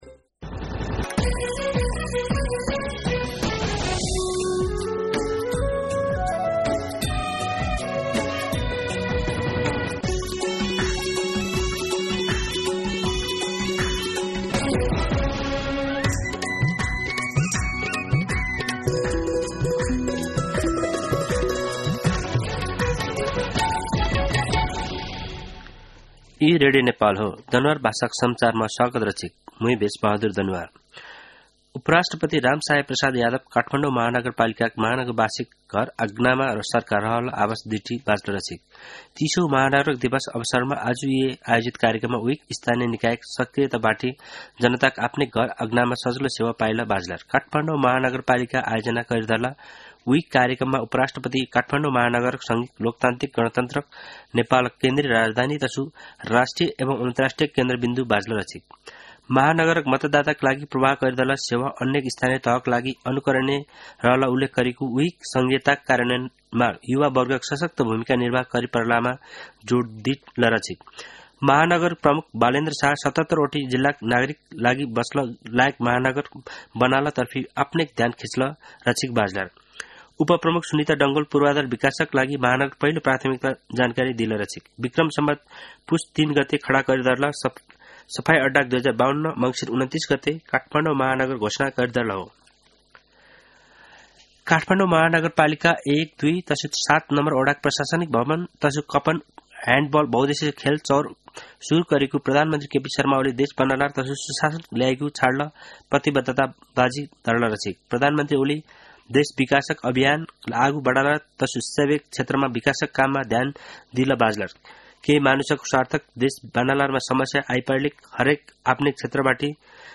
दनुवार भाषामा समाचार : ३० मंसिर , २०८१
Danuwar-News-29.mp3